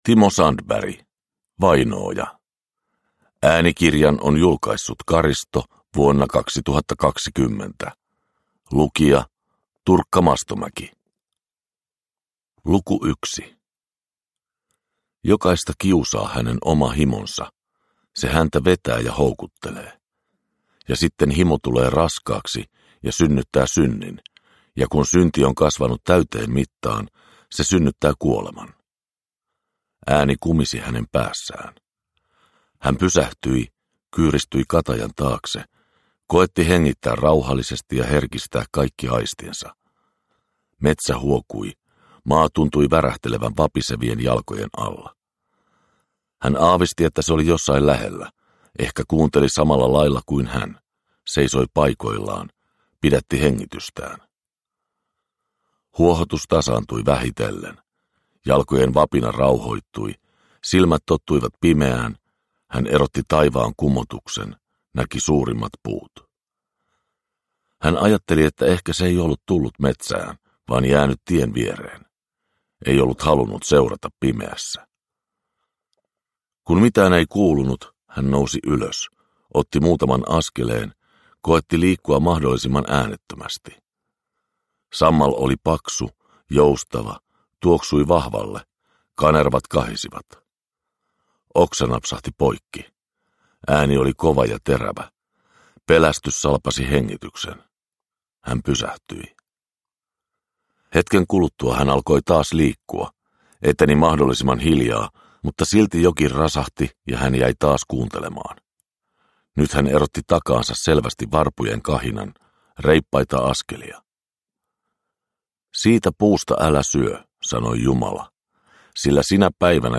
Vainooja – Ljudbok – Laddas ner